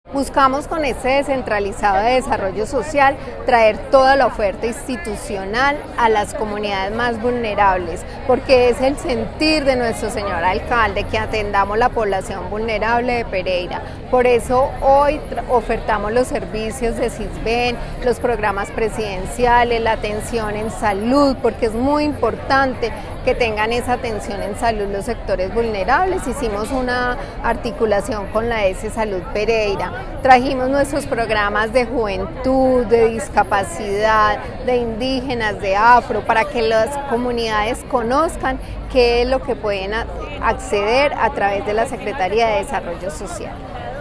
Audio-Martha-Cecilia-Alzate-Secretaria-de-Desarrollo-Social-Y-Politico-mp3cut.net_.m4a